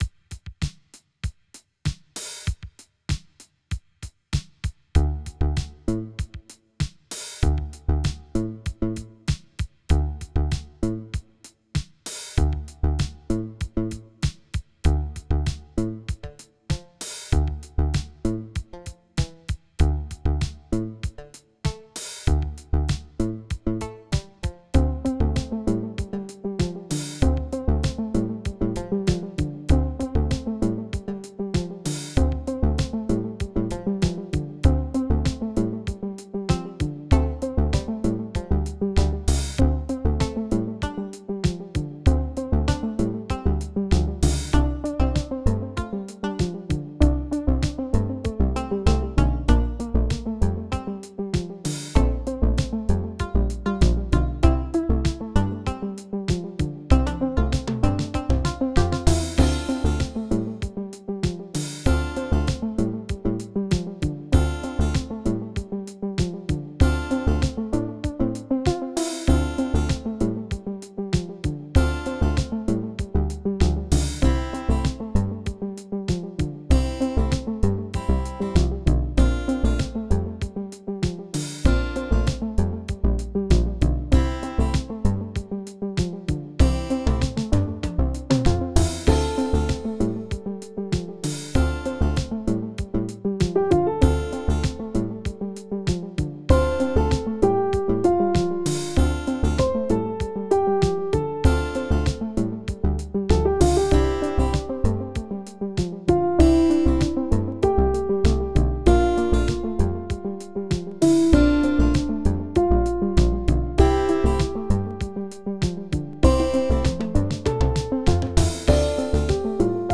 Tags: Piano, Guitar, Percussion, Digital
Title Early Bird Opus # 638 Year 2025 Duration 00:04:03 Self-Rating 4 Description It may help to imagine the strutting walk. mp3 download wav download Files: wav mp3 Tags: Piano, Guitar, Percussion, Digital Plays: 41 Likes: 4